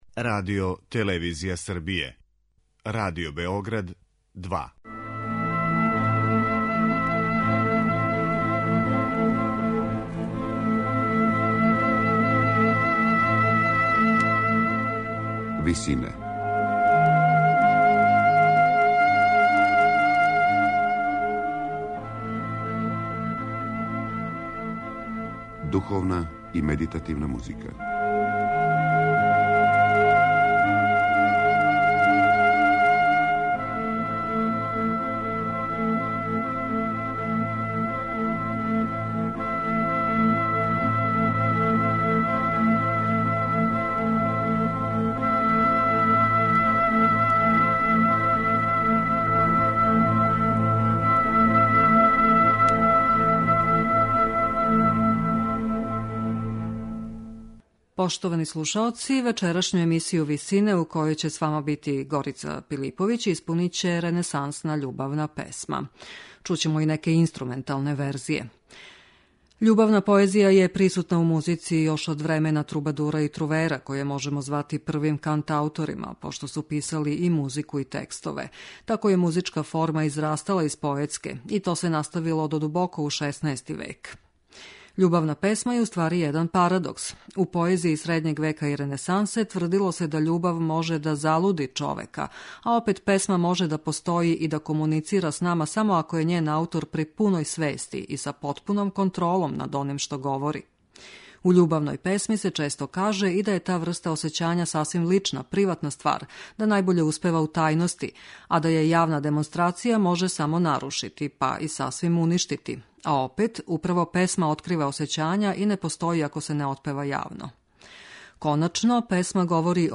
Ренесансне љубавне песме